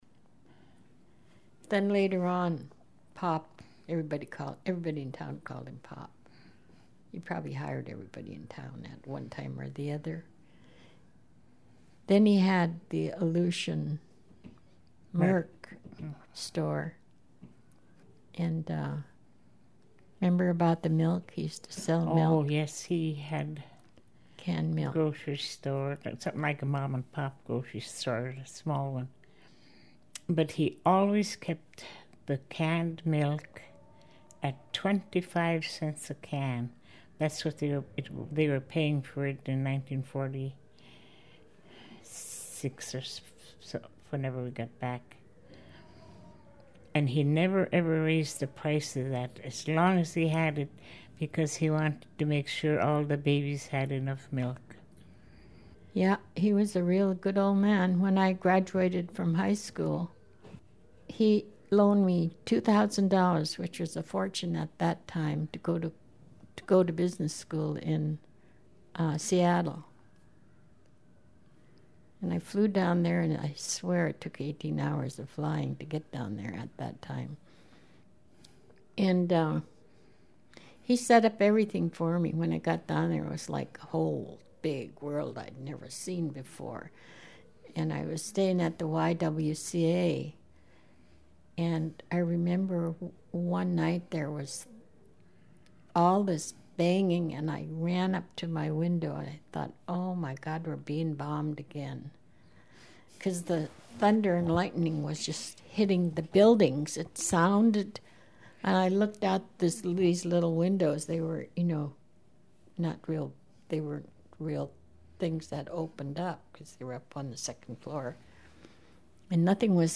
Unalaska, AK – The StoryCorps project records conversations between ordinary people about their lives.